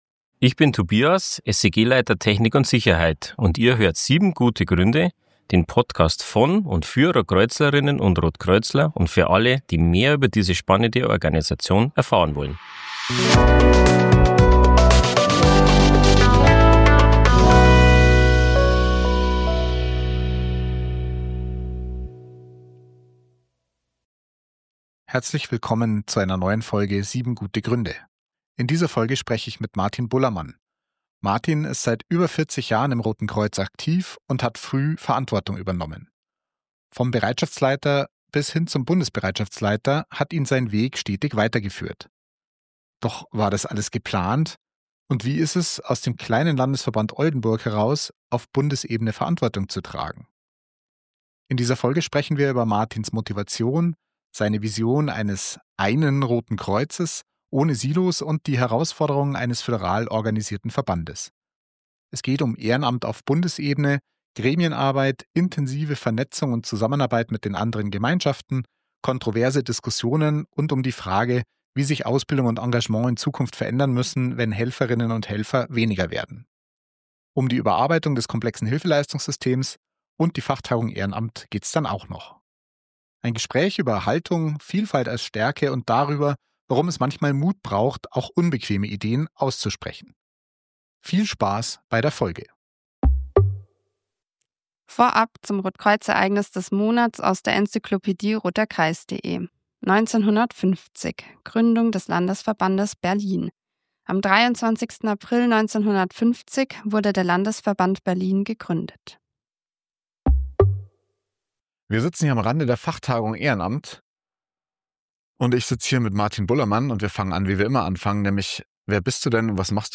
Ein Gespräch über Haltung, Vielfalt als Stärke und darüber, warum es manchmal Mut braucht, auch unbequeme Ideen auszusprechen.